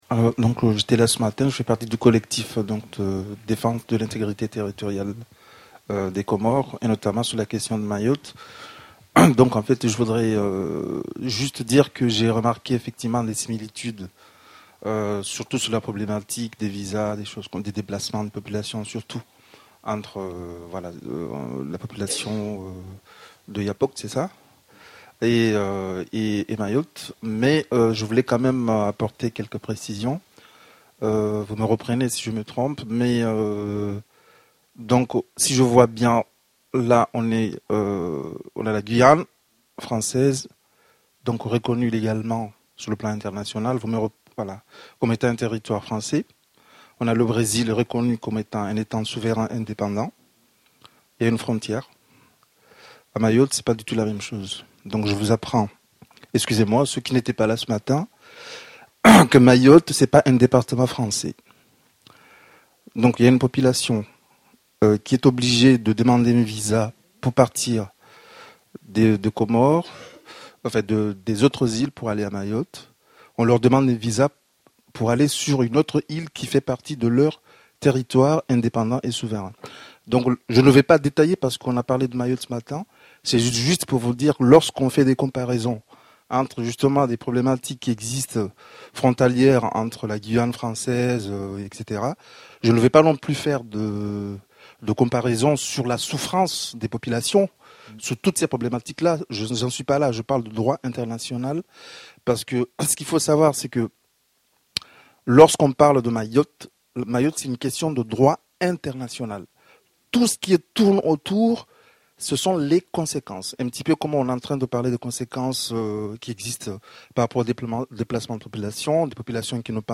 Seconde partie de la conférence consacrée à la semaine anti-coloniale de la FASTI.